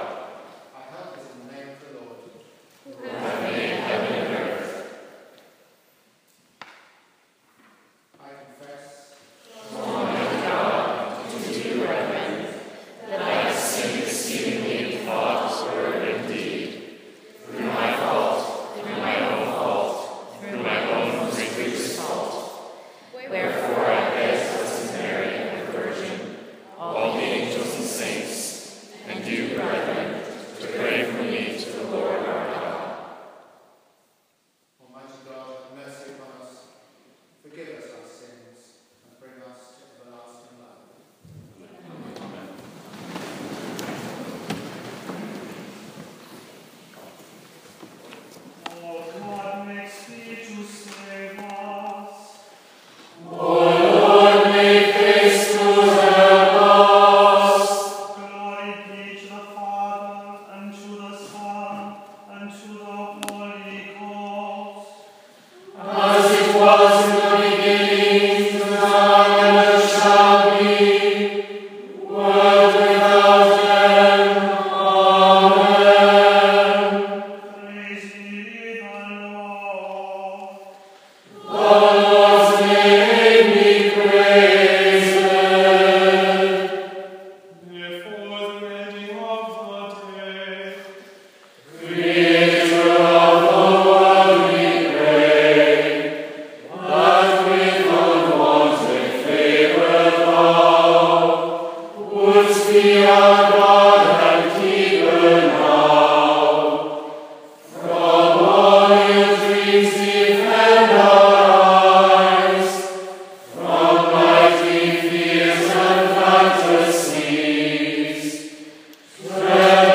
At least the mechanics of chanting were demystified. singing in a space with lovely acoustics and imagining monks singing the same music every night for over 100 years is style awe inspiring and mystical.
Compline Service of St. Stevens House sung by members of Williamson Voices and the Directors attending CMI